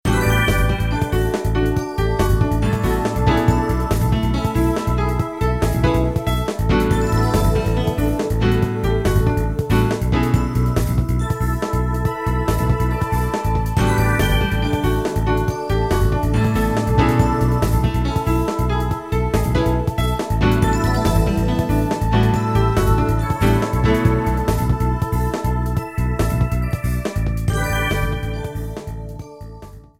The main menu theme